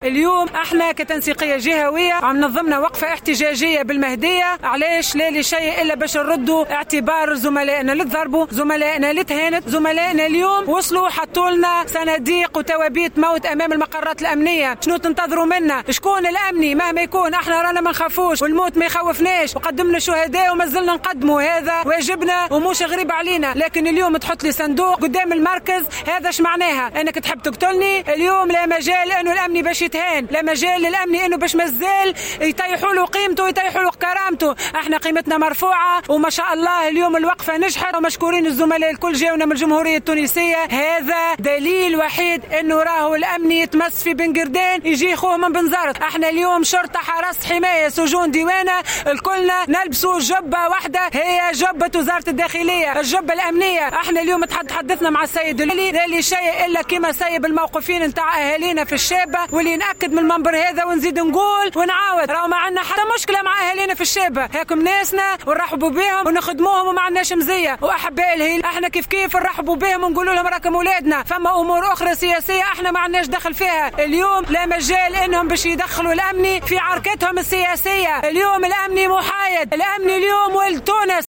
نظمت التنسيقية الجهوية لقوات الامن الداخلي بالمهدية اليوم الخميس 10 ديسمبر2020، وقفة احتجاجية أمام مقر الولاية، كرّد اعتبار للأمنيين الذين تم الاعتداء عليهم مؤخرا.